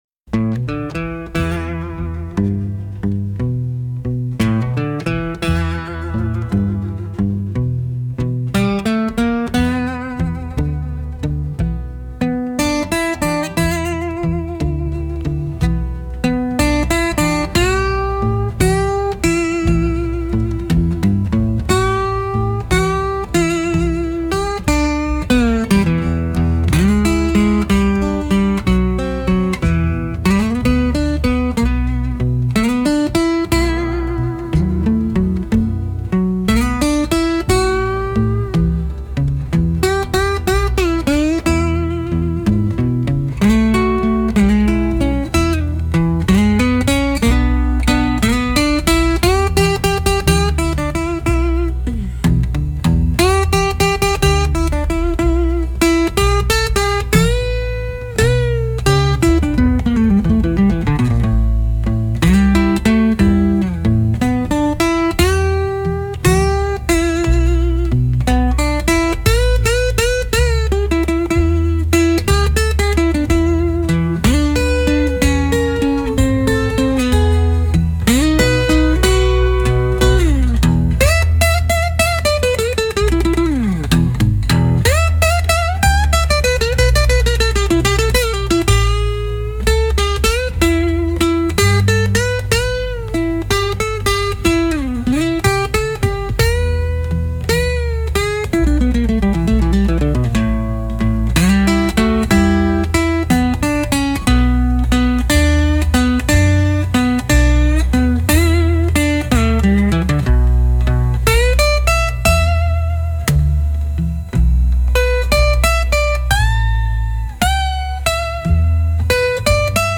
Instrumental - Souls for Silence 4.00 Mins